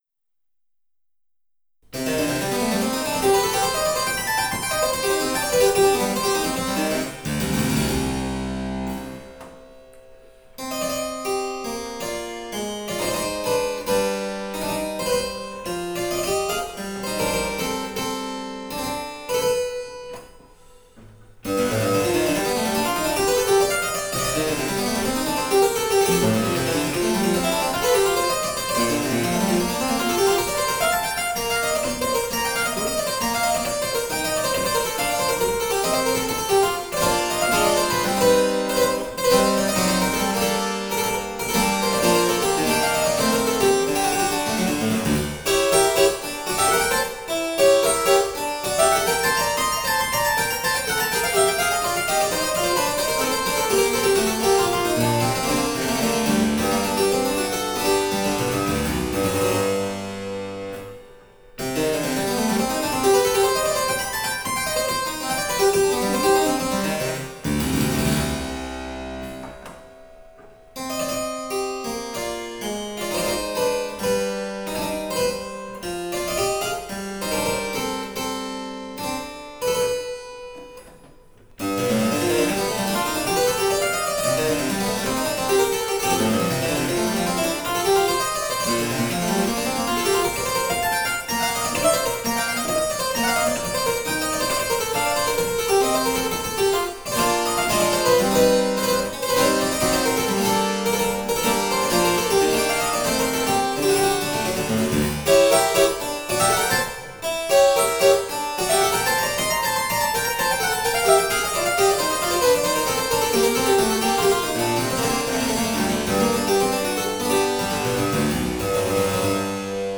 Ce sont des morceaux de virtuosité, pleins de contrastes, et on peut imaginer à les entendre que notre Marie-Anne était une personne vive et séduisante, si tant est que ces portraits musicaux soient ressemblants.